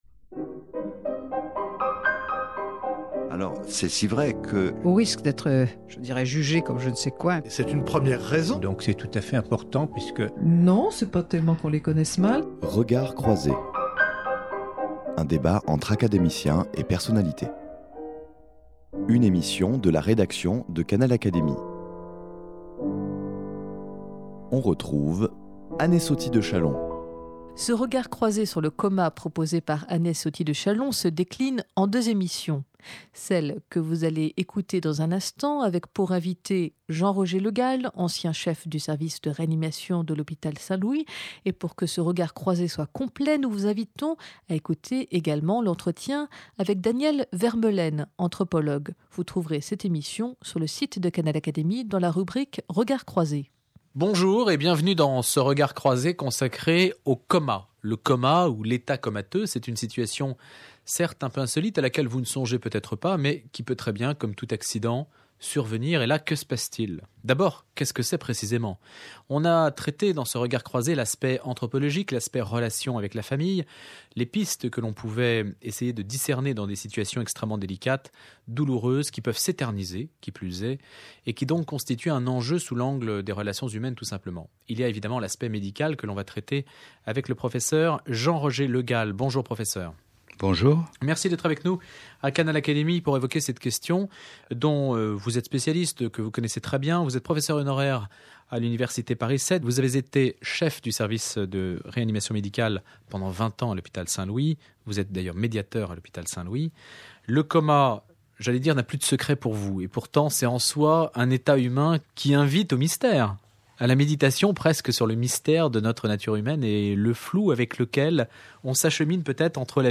Plus globalement, cet entretien met en évidence un faisceau de situations médicalement et humainement délicates.